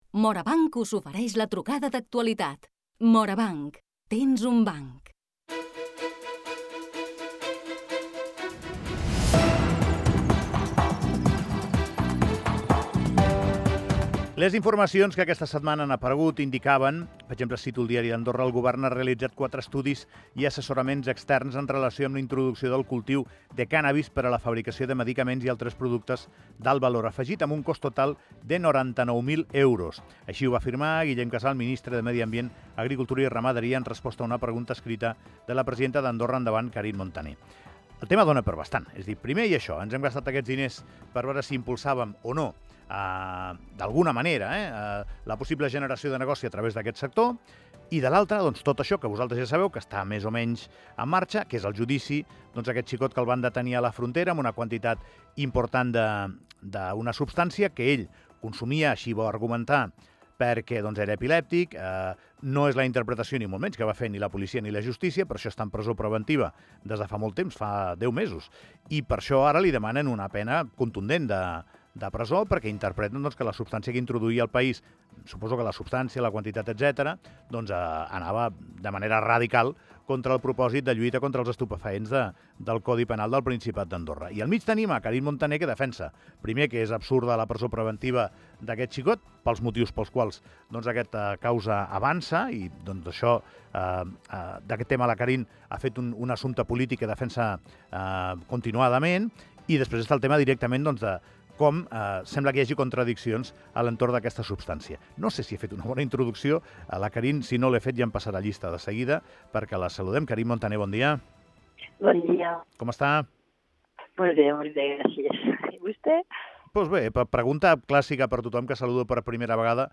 Carine Montaner és la protagonista de la trucada d'actualitat i el CBD, de nou, protagonista
El Govern ha realitzat quatre estudis i assessoraments externs en relació amb la introducció del cultiu de cànnabis per a la fabricació de medicaments i altres productes d’alt valor afegit, amb un cost total de 99.000 euros. La presidenta del grup parlemantari Andorra Endavant Carine Montaner ha valorat aquesta informació a la trucada d'actualitat.